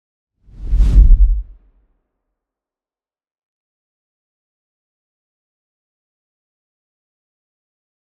Whoosh Large Sub Sound Button - Free Download & Play